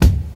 Tuned drums (D key) Free sound effects and audio clips
• Studio Bass Drum Sample D Key 137.wav
Royality free kick drum sound tuned to the D note. Loudest frequency: 455Hz
studio-bass-drum-sample-d-key-137-bhu.wav